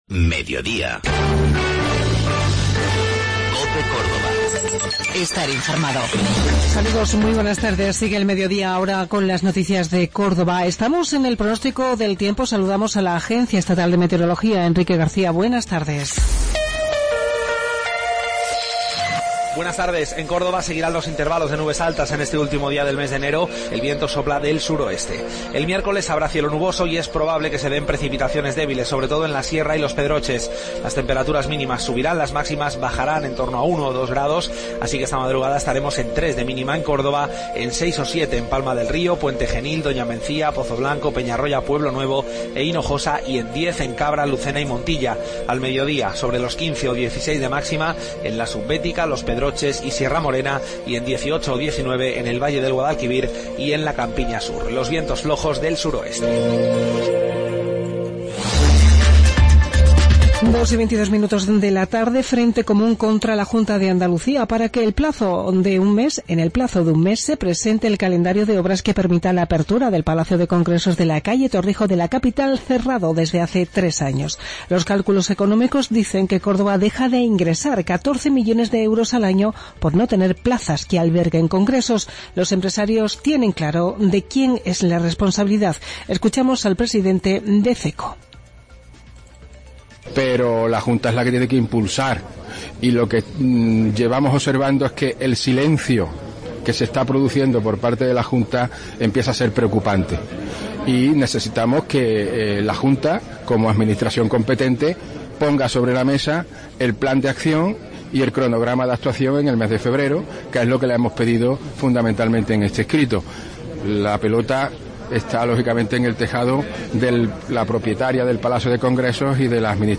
Mediodía en Cope. Informativo local 31 de Enero 2017